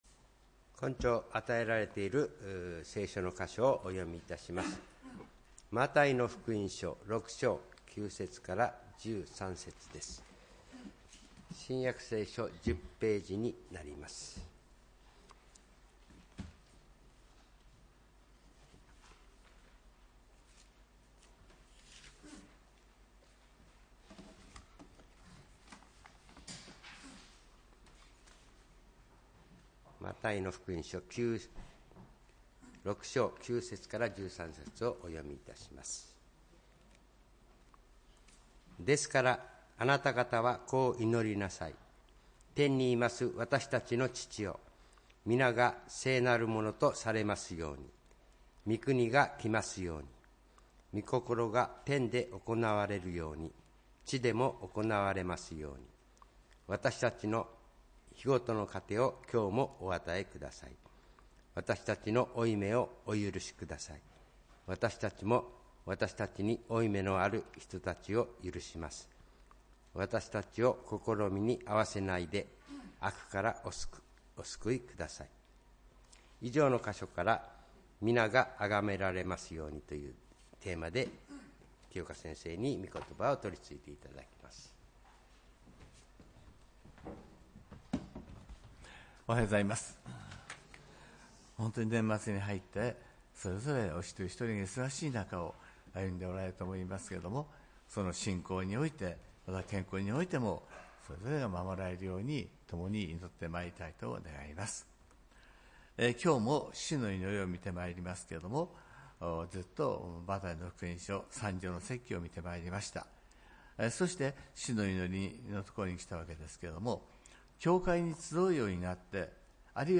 礼拝メッセージ「み名があがめられますように」(12月８日）